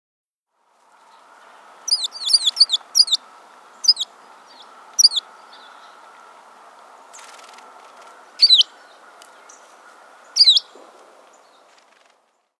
American Tree Sparrow
How they sound: Their call, a musical twitter, sounds like teel-wit or teedle-eet.